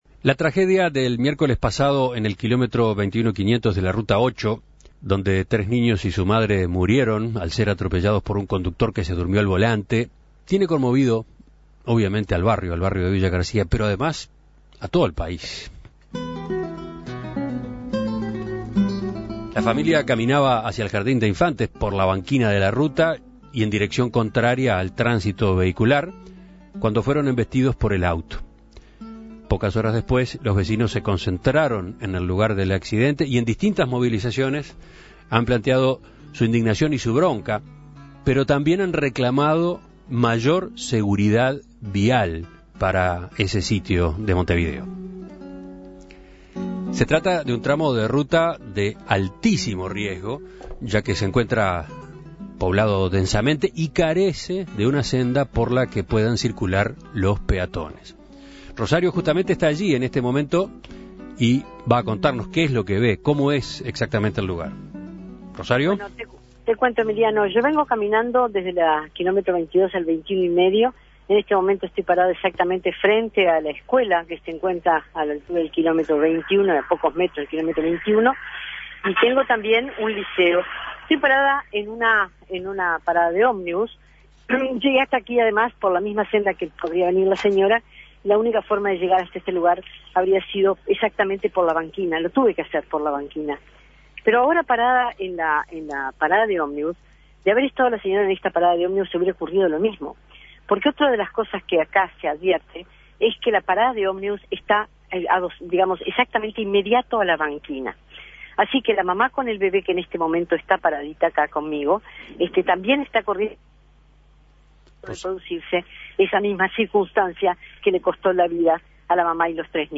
desde el km 21.500- Villa García donde ocurrió la tragedia el pasado miércoles.